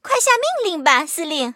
M2中坦司令部语音2.OGG